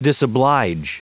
Prononciation du mot disoblige en anglais (fichier audio)
Prononciation du mot : disoblige